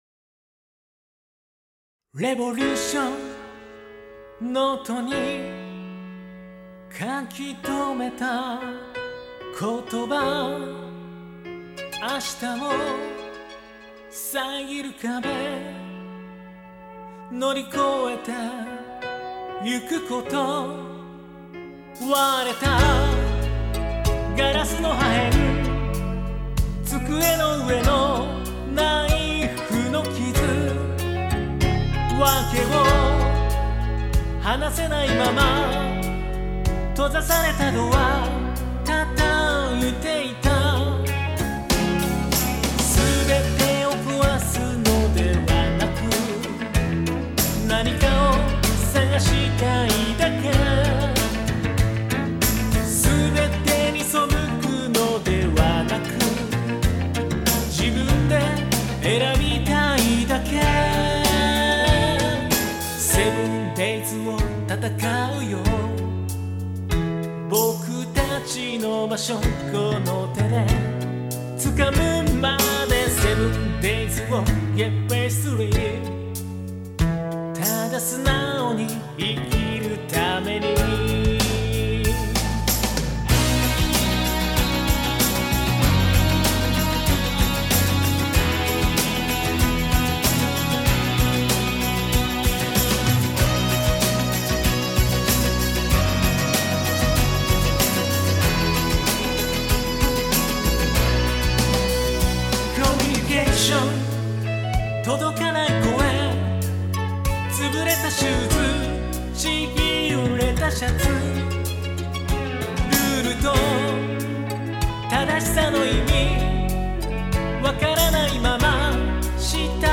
名バラード
キーも高くないので歌っていて楽しい。